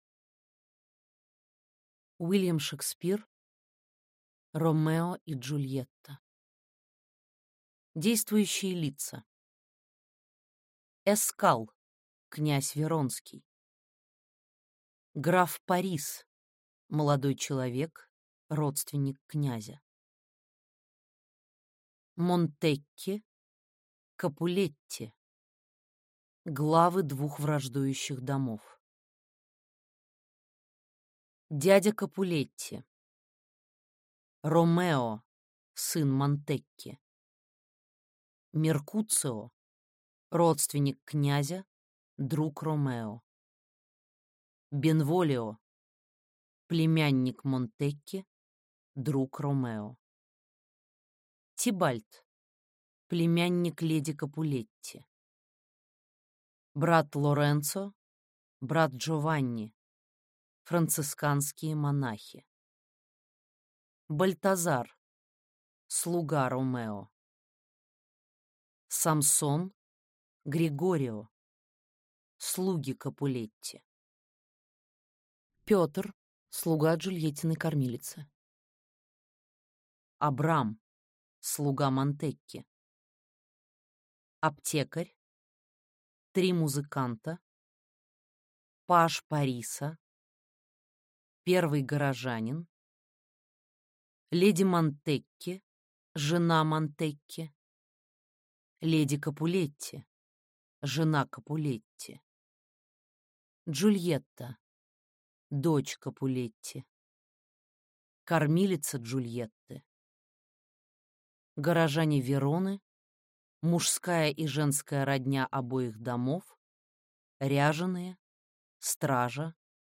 Аудиокнига Ромео и Джульетта | Библиотека аудиокниг